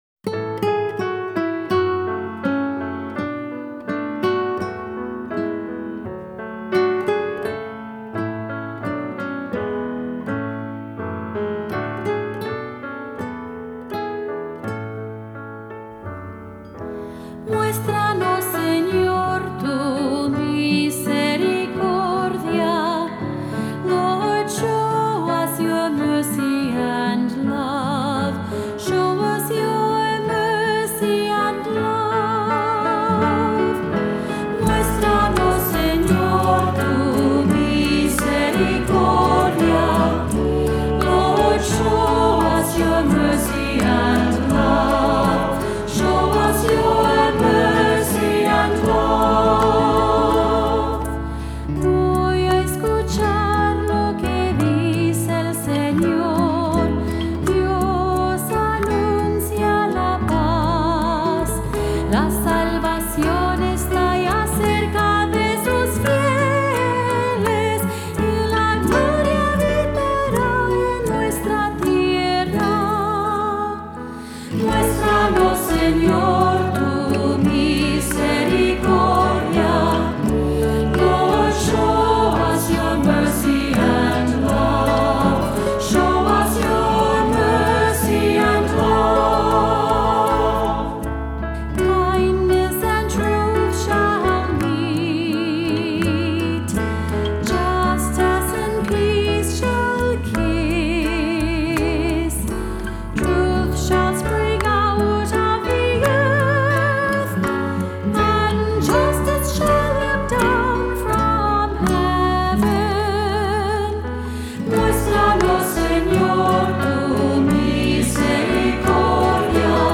Voicing: SATB, cantor, assembly